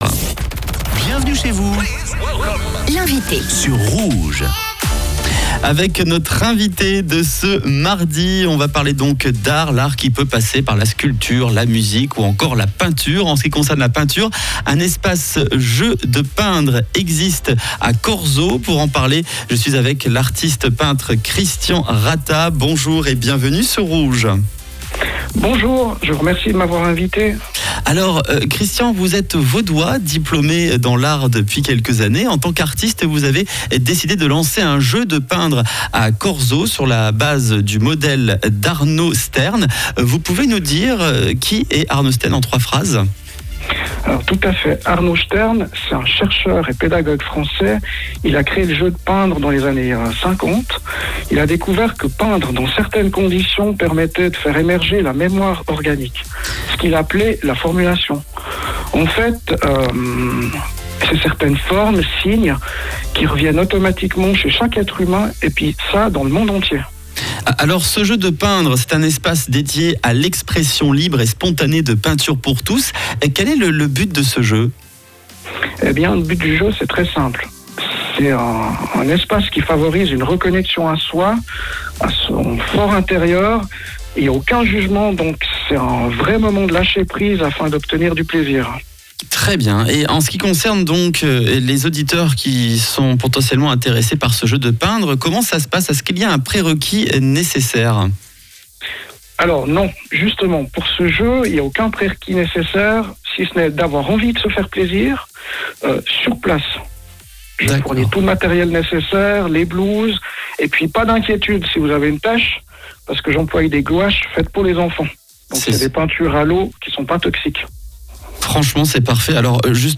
Interview Rouge FM sur l’atelier Jeu de Peindre Corseaux